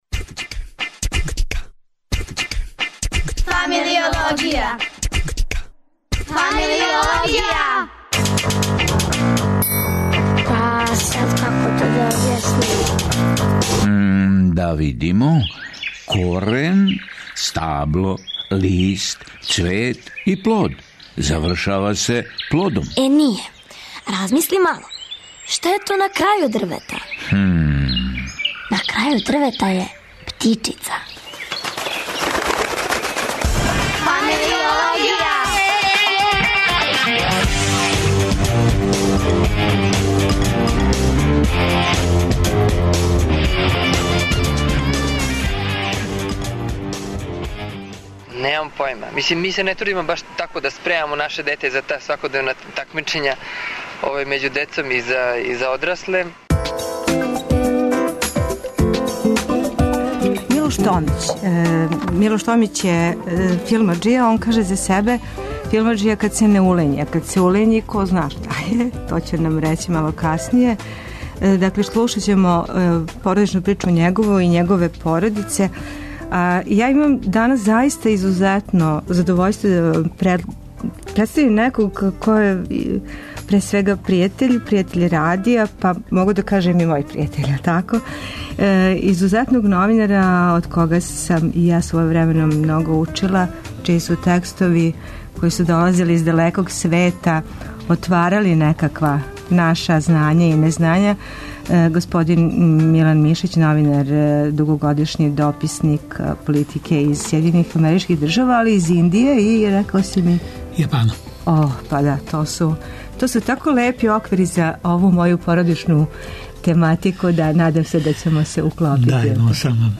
Радио Београд 1, 13.05